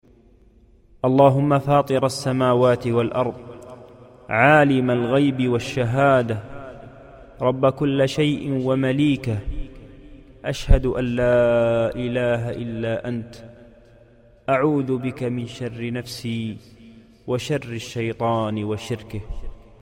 القسم: الأدعية والأذكار